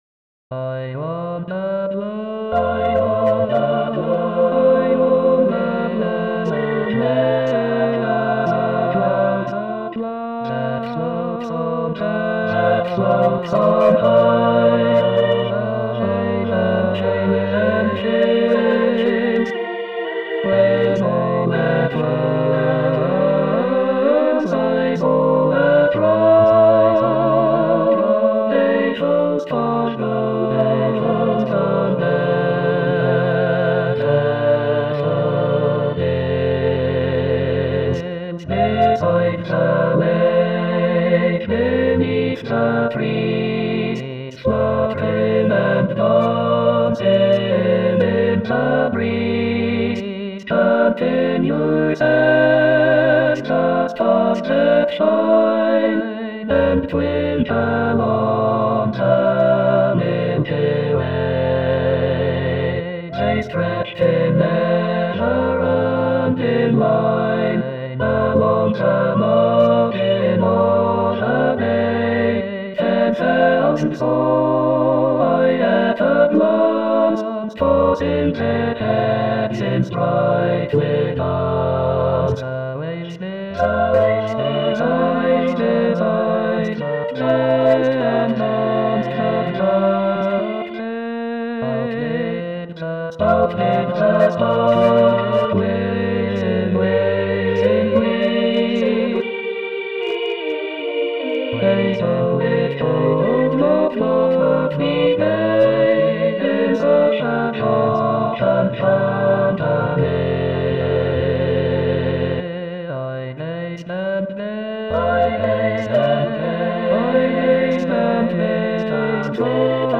William Wordsworth Number of voices: 5vv Voicing: SSATB Genre: Secular, Partsong
Language: English Instruments: A cappella